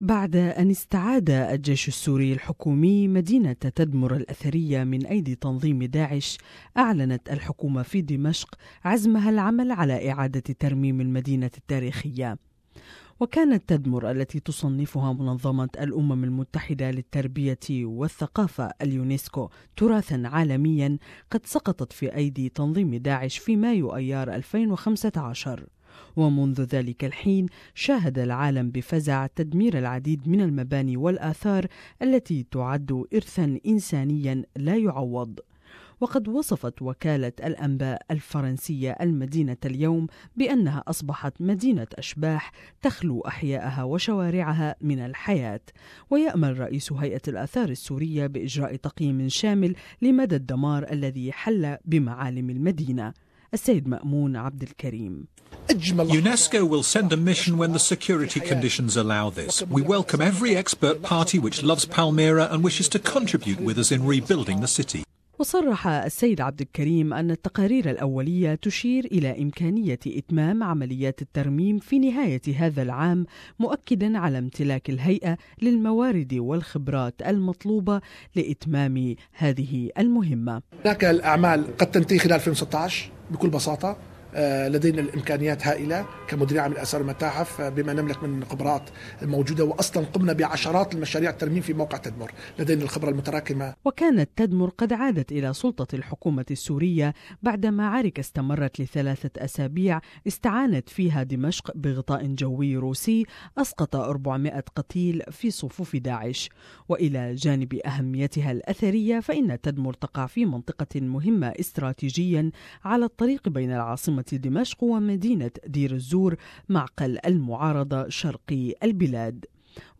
عادت تدمر الى سلطة الحكومة السورية التي وعدت باعادة ترميم معالم المدينة التاريخية بعد تحريرها من ايدي داعش المزيد في التقرير التالي